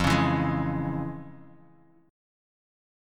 FmM7#5 Chord
Listen to FmM7#5 strummed